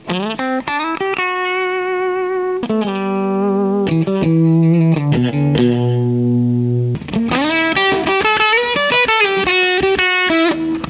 COMPRESSORS